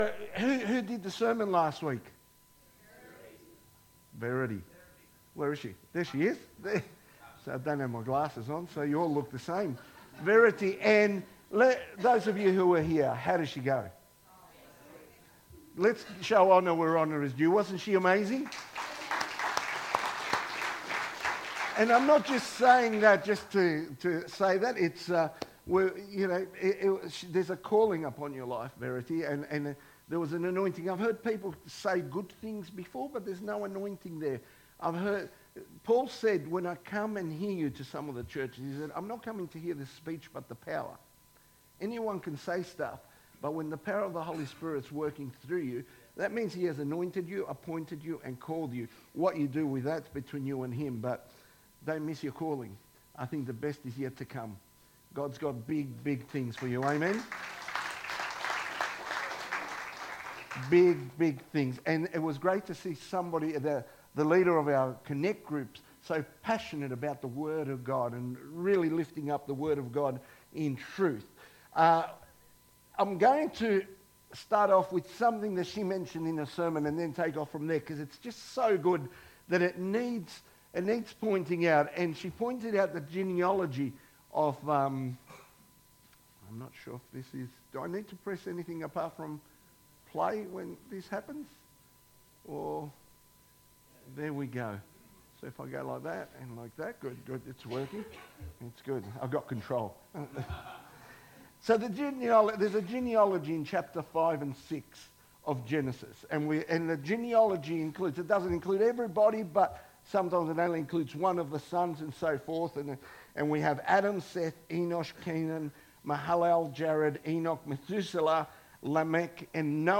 Sermons | Wonthaggi Baptist Church